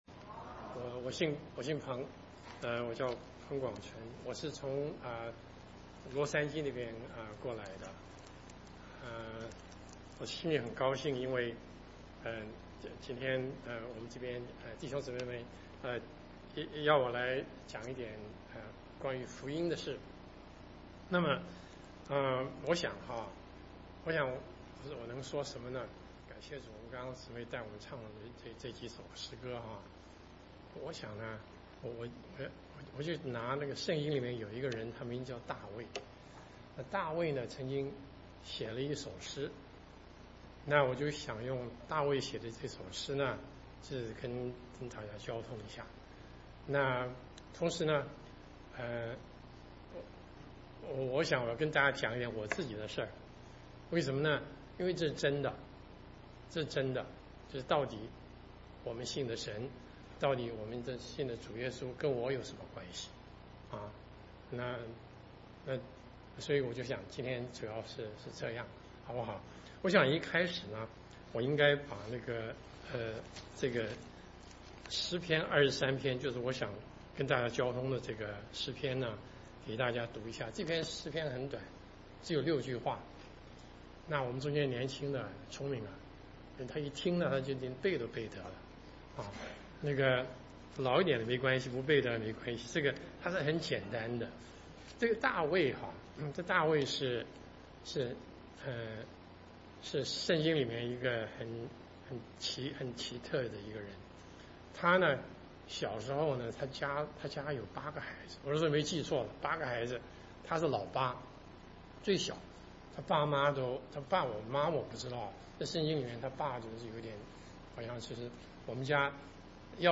東區基督教會福音講道信息